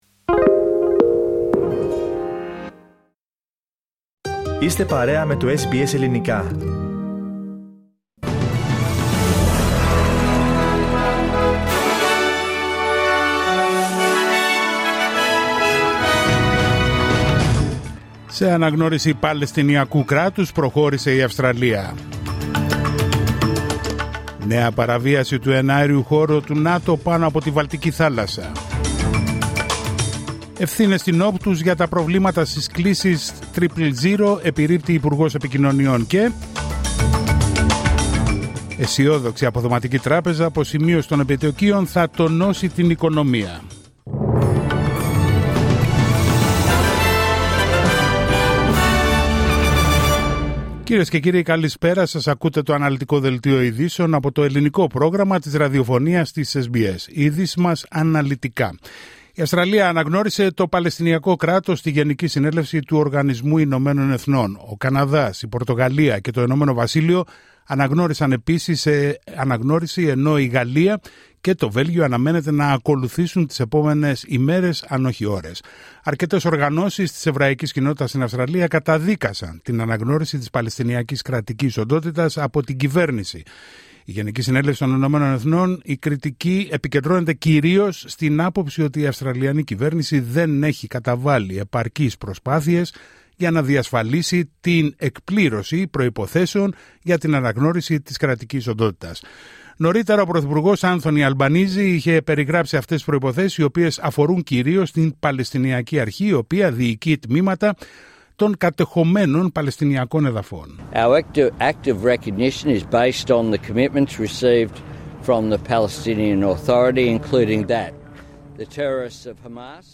Δελτίο ειδήσεων Δευτέρα 22Σεπτεμβρίου 2025